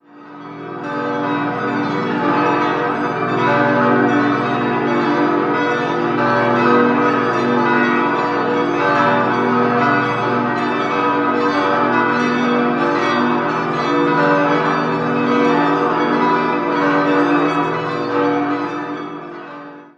16-stimmiges Geläute: h°-d'-e'-fis'-gis'-a'-h'-d''-e''-fis''-a''-h''-cis'''-d'''-e'''-fis'''
bell
Einige der Glocken sind seit mehreren Jahren mit Obergewichten und Reversionsklöppeln ausgestattet, was man den Glocken beim Einzelläuten zum Teil sehr deutlich anhört. Im Vollgeläute jedoch fallen diese klanglichen Einschränkungen kaum auf, der Gesamteindruck des Glockenchores steht hier im Vordergrund.